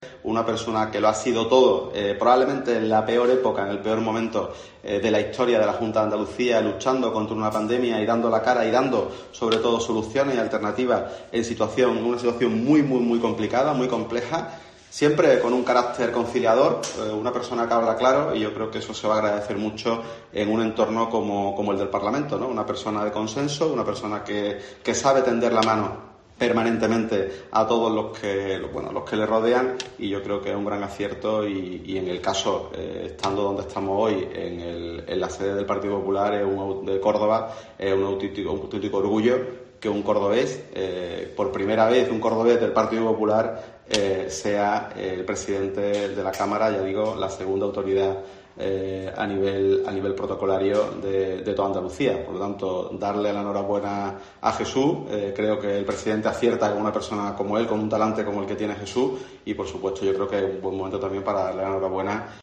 Escucha a Antonio Repullo sobre el anuncio de Juanma Moreno
Así lo señaló este miércoles en rueda de prensa en Córdoba el coordinador general del PP de Andalucía, Antonio Repullo, afirmando que se trata de un reconocimiento a "la labor importantísima y brillante que Aguirre ha realizado en la gestión sanitaria", al mismo tiempo que es "toda una declaración de intenciones para la nueva legislatura elegir un perfil como éste para presidir el Parlamento andaluz, por su humanidad, empatía y diálogo".